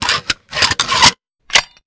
antitank_reload_01.wav